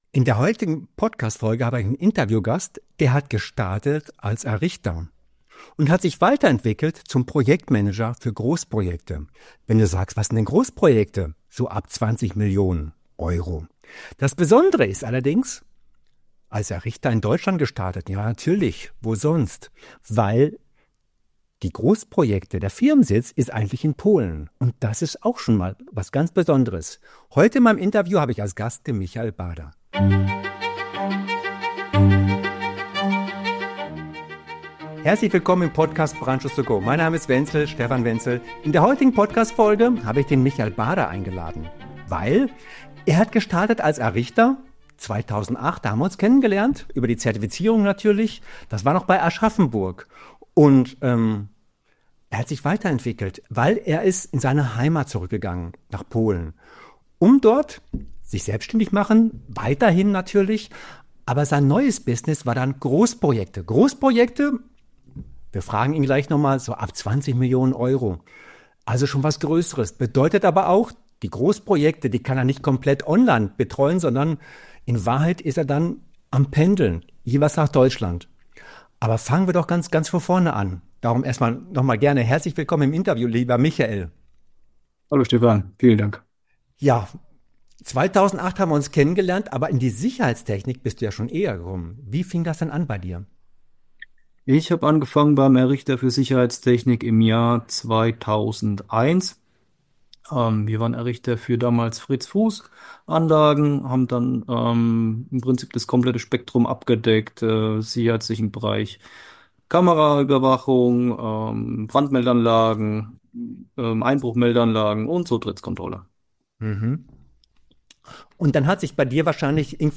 Ein spannendes Gespräch über Weiterentwicklung, mutige Entscheidungen, Kommunikation auf Großbaustellen und warum Lernen nie aufhört – selbst nach vielen Jahren Berufserfahrung. Ein ehrlicher Einblick in einen Karriereweg, der zeigt, was im Brandschutz und in der Sicherheitstechnik möglich ist.